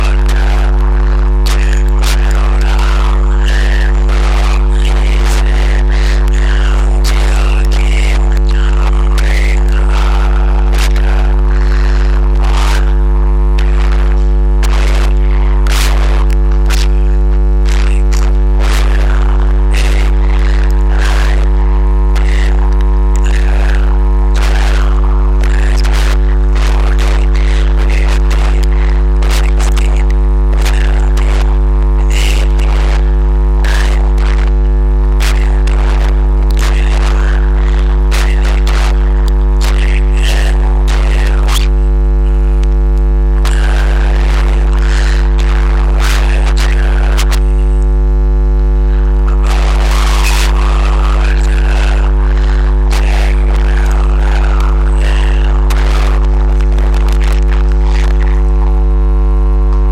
Rather than using the loop receiver I left an open end cable in the magnetic loop area and amplified this signal with an amplifier and into the computer which is recording this.  So it is an open end cable that is recording this signal.